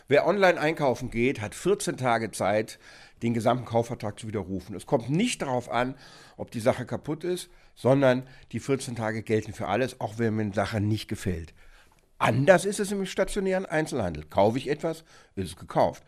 O-Ton1: Umtausch, Reklamation, Widerruf: Wann darf man Waren zurückgeben?